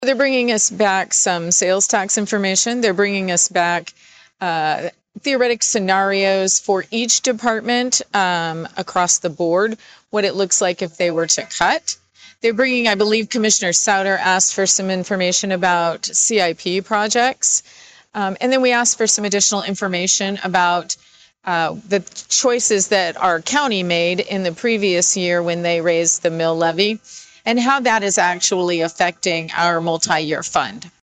Commissioner Susan Brinkman requested additional information from city staff before making any decisions.